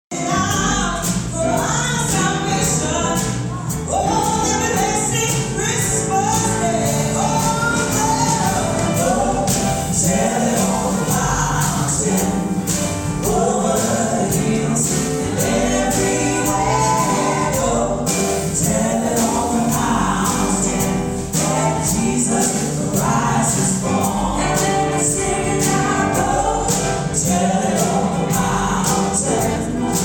brought the spirit of winter and plenty of soul to the Emporia Granada Theatre Friday night
a selection of traditional holiday melodies with a classic blues feel